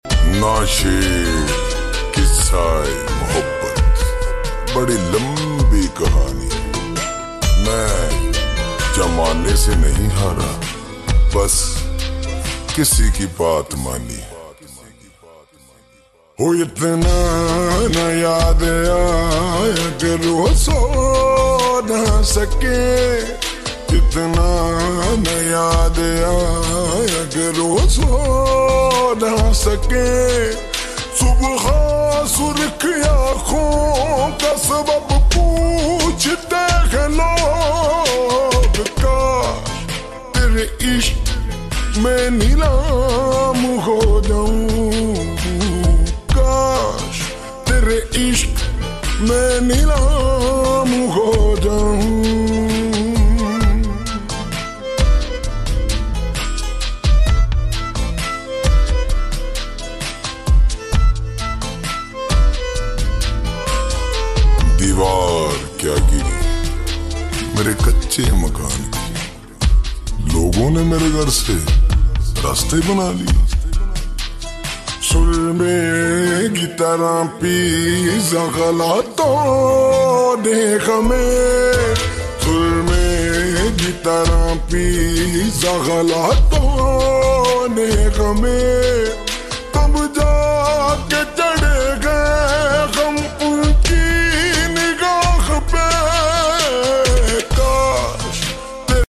Sad Song Slowed and Reverb